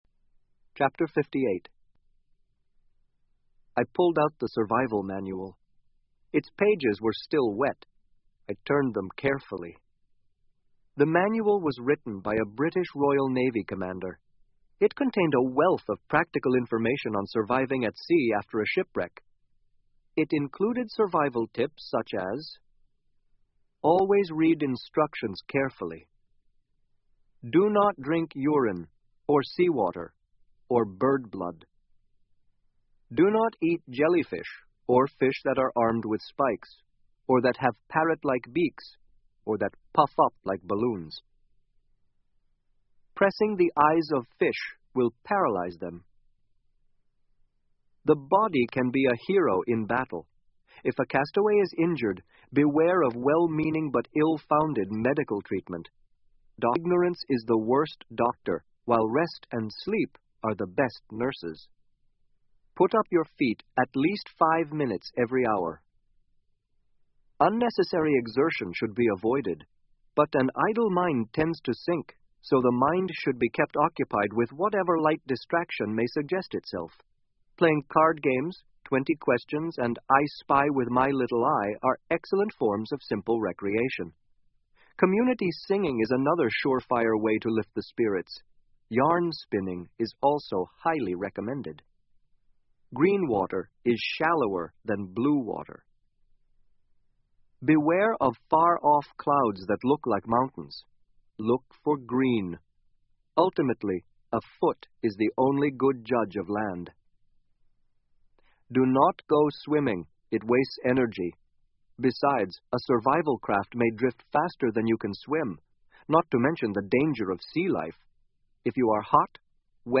英文广播剧在线听 Life Of Pi 少年Pi的奇幻漂流 05-08 听力文件下载—在线英语听力室